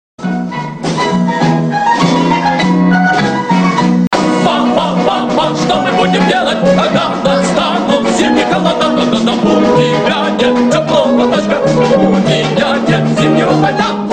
веселые
хор